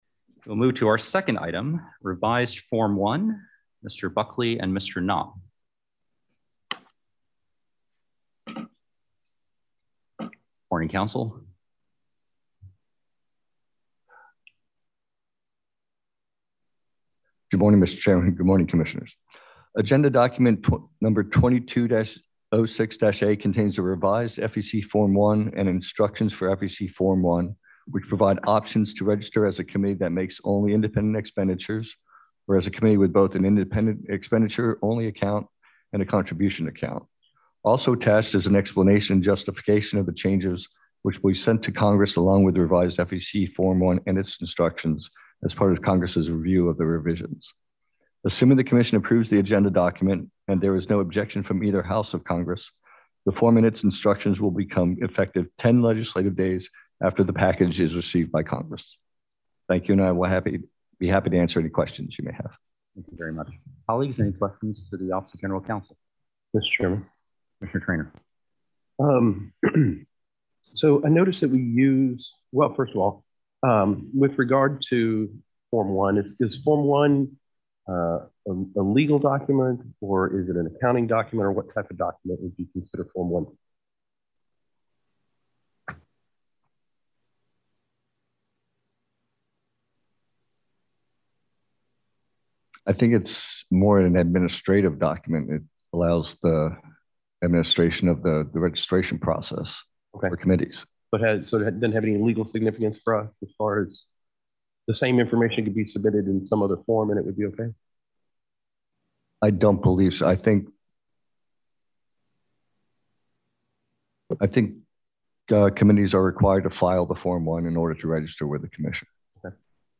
March 10, 2022 open meeting of the FEC